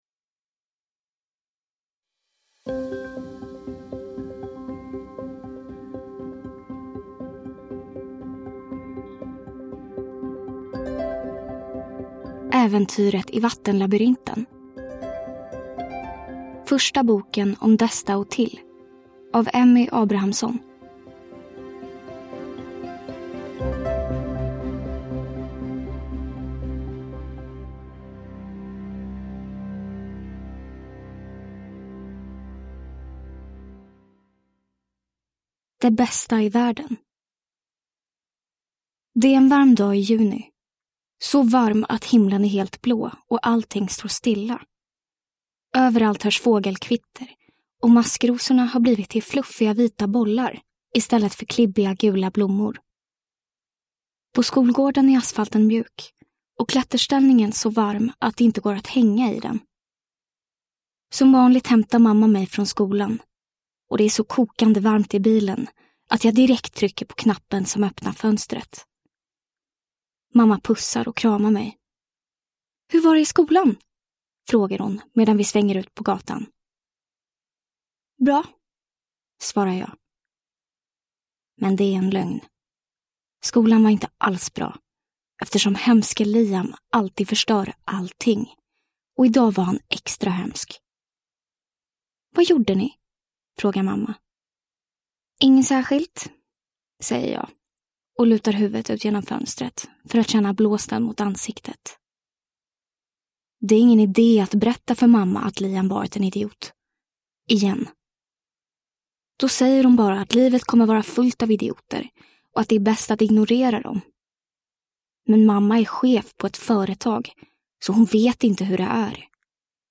Äventyret i vattenlabyrinten – Ljudbok